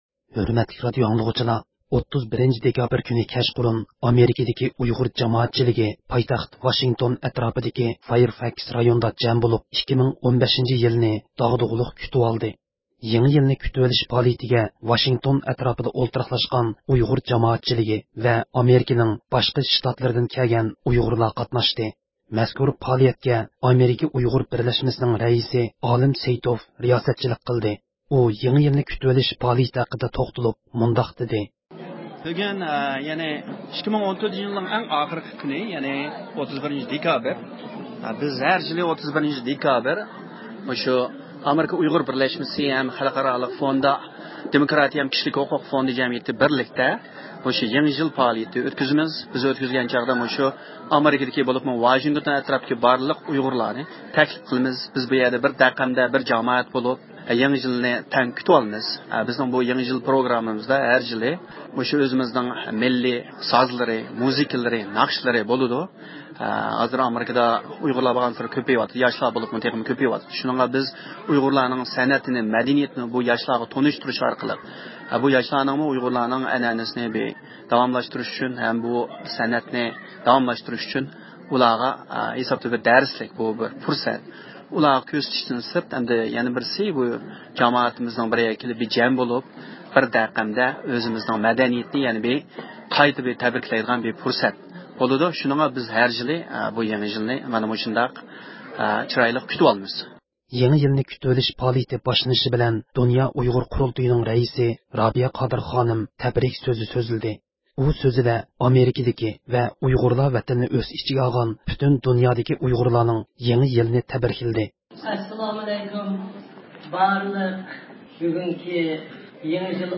31-دېكابىر كۈنى كەچقۇرۇن ئامېرىكىدىكى ئۇيغۇر جامائەتچىلىكى پايتەخت ۋاشىنگتون ئەتراپىدىكى فايرفاكس رايونىدا جەم بولۇپ 2015-يىلىنى داغدۇغىلىق كۈتۈۋالدى.
پائالىيەت داۋامىدا ئەر-ئاياللارنىڭ كوللېكتىپ ئىجراسىدىكى ئومۇمىخور – «بىزنىڭ ناخشىمىز» مىللىي چالغۇلارنىڭ تەڭكەش قىلىنىشى بىلەن ئورۇنلاندى.
ياش گىتار ماھىرلىرىنىڭ ئورۇنلىشىدىكى لىرىك ناخشا-مۇزىكىلارمۇ پائالىيەتكە قەدەم تەشرىپ قىلغان جامائەتچىلىككە گۈزەل كەيپىيات بېغىشلىدى.